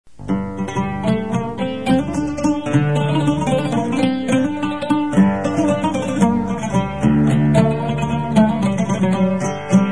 Instruments traditionnels arméniens
Oud
L'oud a 11 cordes, 10 couplées et une basse placée habituellement sous les chanterelles, et se joue à l'aide d'une plume d'aigle ébarbée et aplatie servant de médiator ; le joueur le tient entre le pouce et l'index, placés en croix, pour pincer les cordes de l'instrument.
oud.mp3